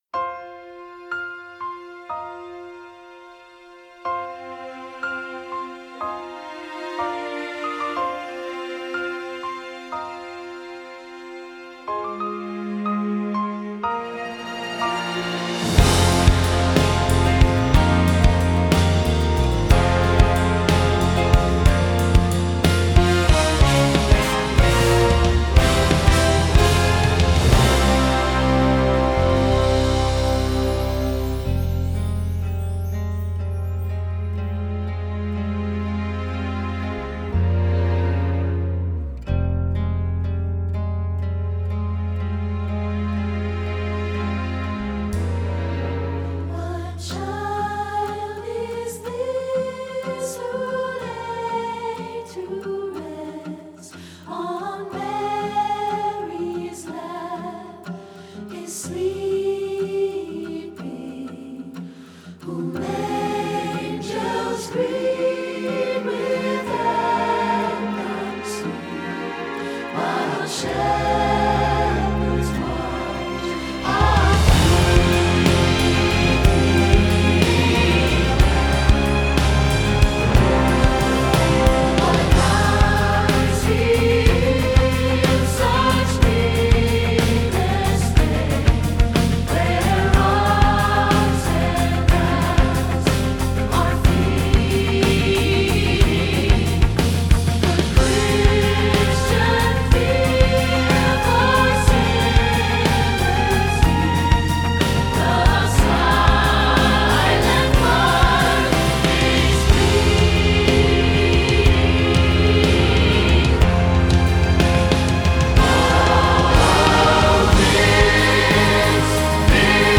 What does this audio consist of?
Voicing: SATB,Pno